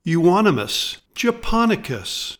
Pronounciation:
U-ON-o-mus ja-PON-i-cus